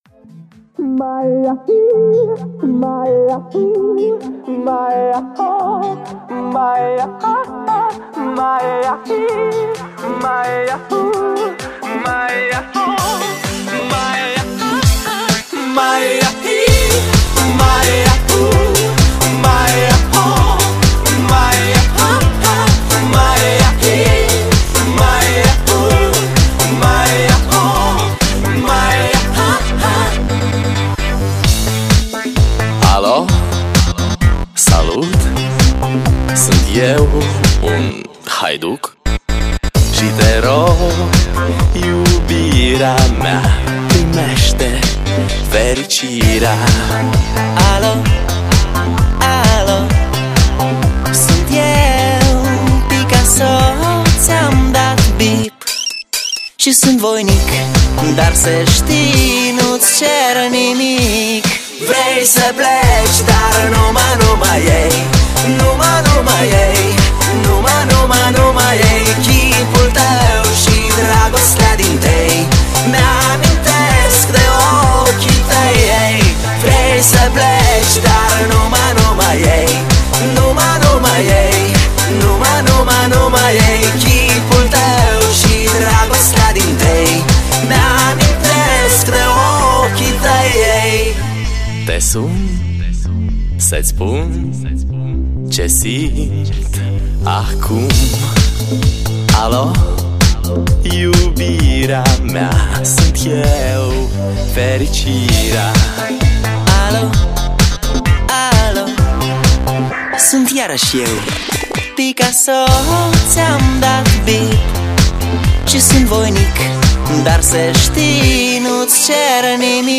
我听这歌的时候大概是04 年还是05年 来自罗马尼亚的一个乐队 被中文翻唱的很难听
翻唱的太恶心了，原版好听多了，好像是四个人合唱的。